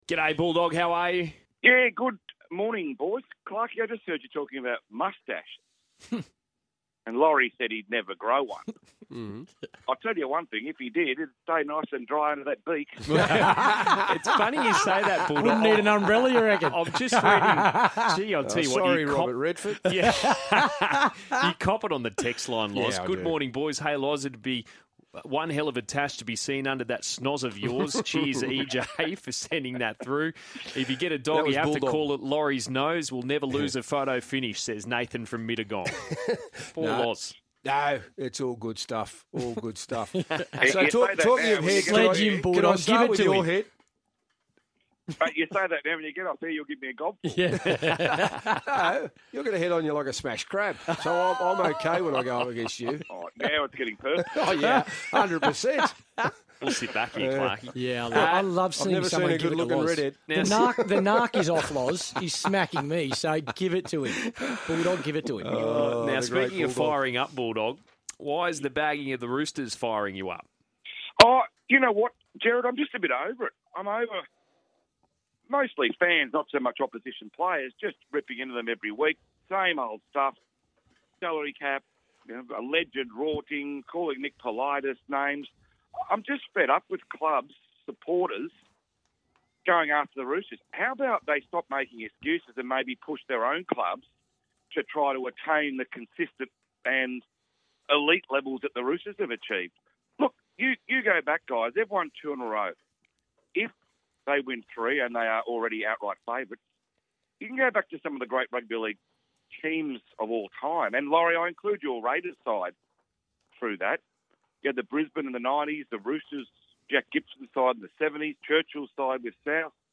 on Sky Sports radio